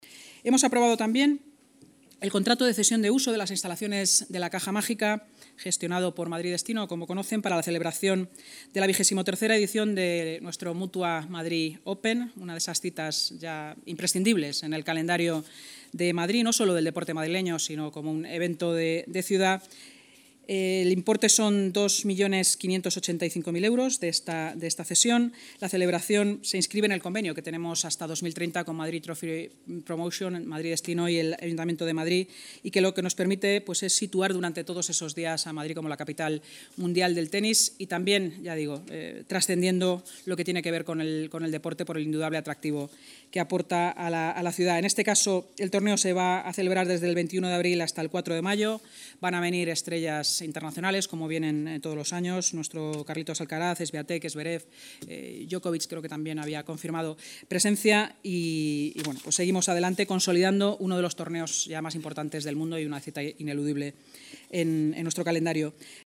Nueva ventana:La vicealcaldesa y portavoz municipal, Inma Sanz: